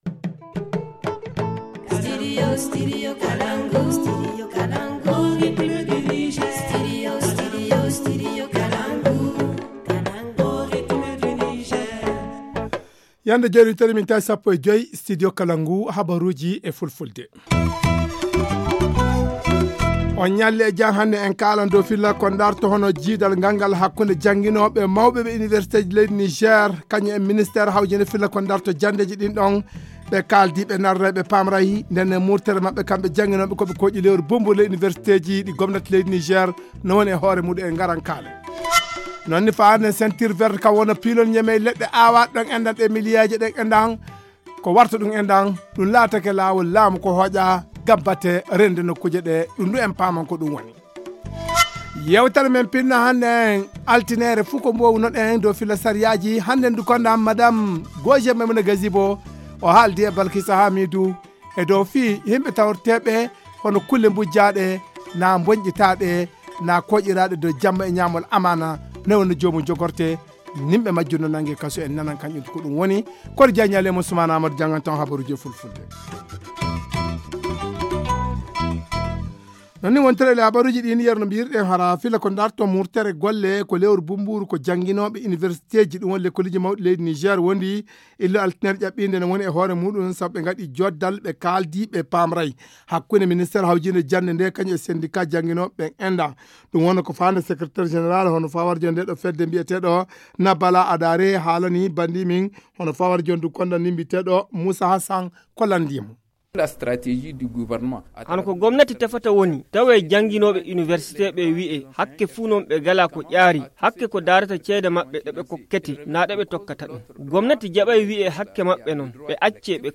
Le journal du 27 janvier 2020 - Studio Kalangou - Au rythme du Niger